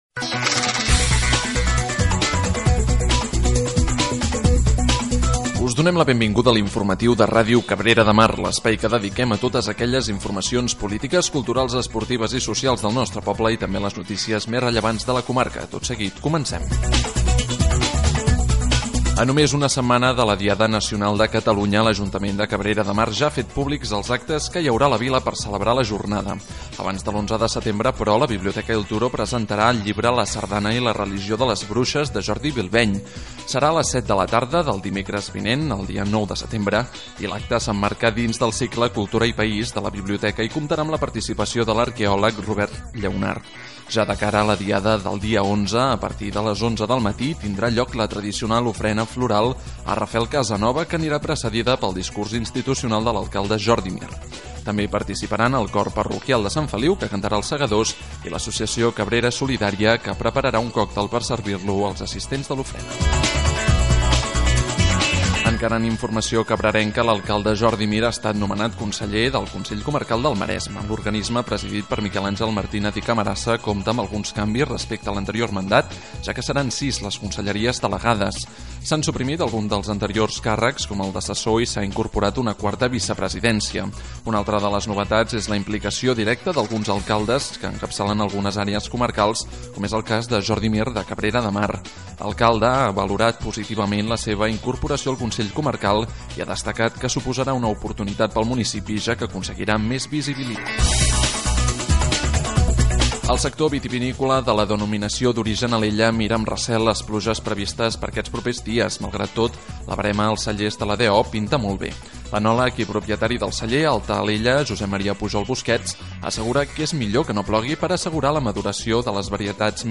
Careta del programa, actes previstos per a la Diada Nacional de Catalunya, canvis al Consell Comarcal del Maresme, la verema al Maresme, acollida de refugiats, Argentona, Escola Municipal de Música de Llavaneres, etc. Careta de sortida i indicatiu de l'emissora.
Informatiu